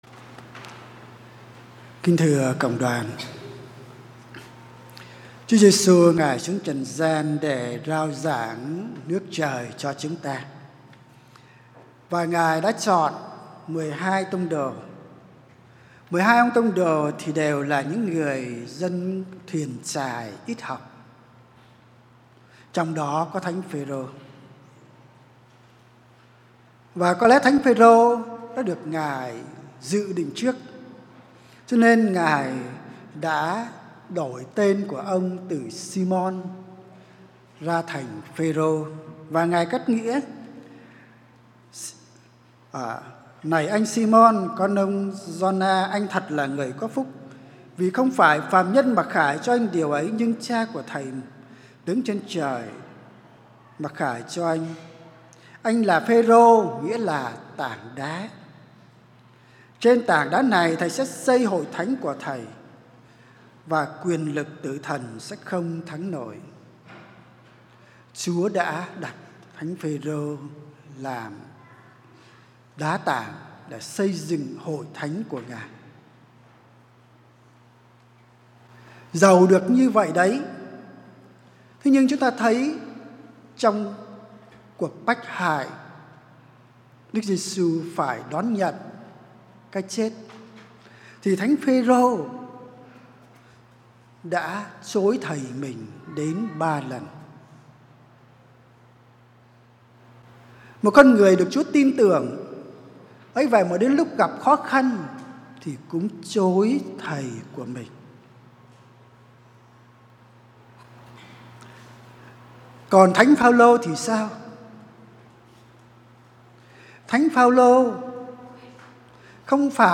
* Thể loại: Nghe giảng